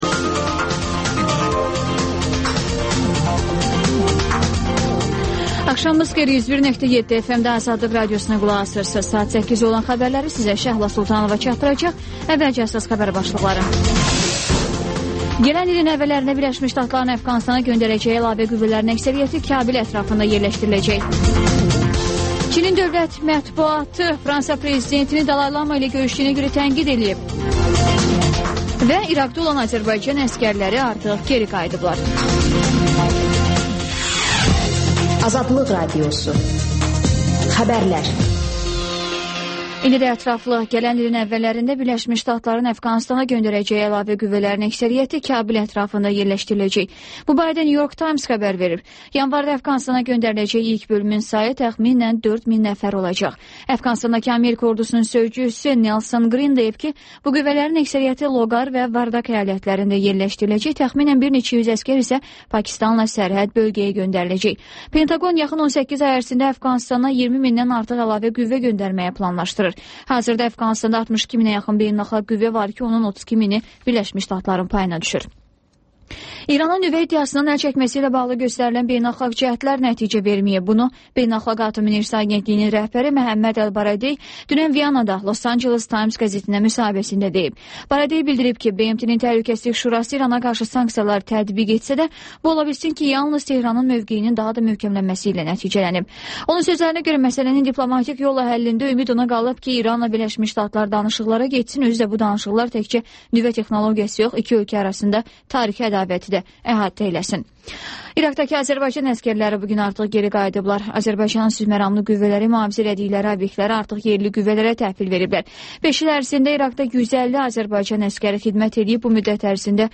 Xəbərlər, İZ: Mədəniyyət proqramı və TANINMIŞLAR rubrikası: Ölkənin tanınmış simaları ilə söhbət